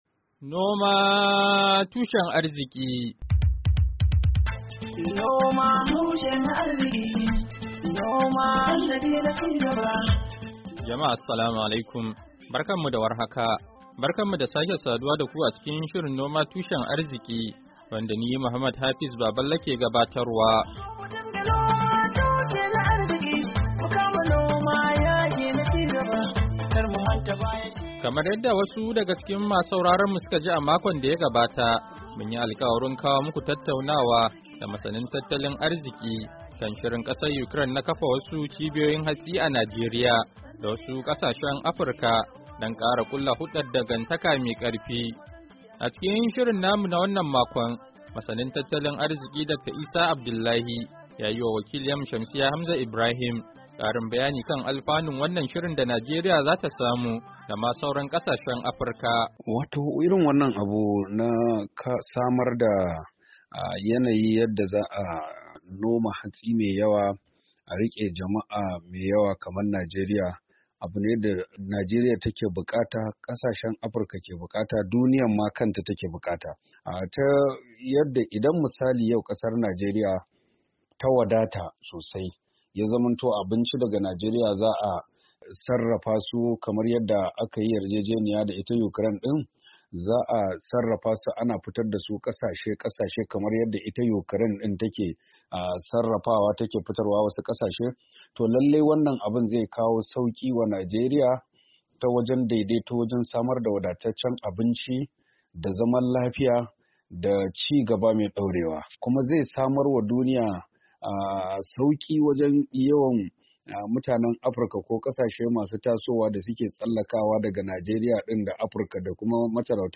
Shirin na wannan makon, ya tattauna da masanin tattalin arziki da dan kasuwa kan shirin kasar Ukraine na kafa cibiyoyin hatsi a Najeriya.